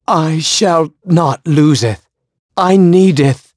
Siegfried-Vox_Dead.wav